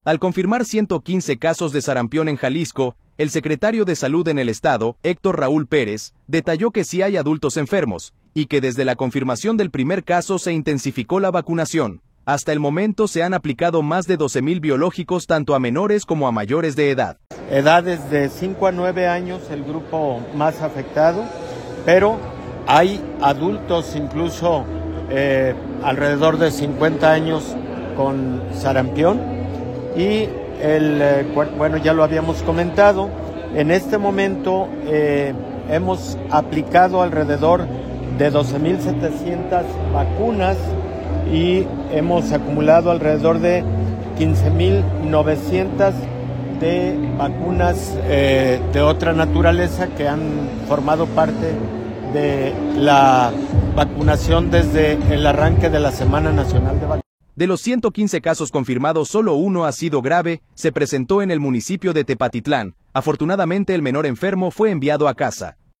Al confirmar 115 casos de sarampión en Jalisco, el secretario de Salud en el Estado, Héctor Raúl Pérez, detalló que sí hay adultos enfermos, y que desde la confirmación del primer caso se intensificó la vacunación. Hasta el momento se han aplicado más de 12 mil biológicos tanto a menores como a mayores de edad.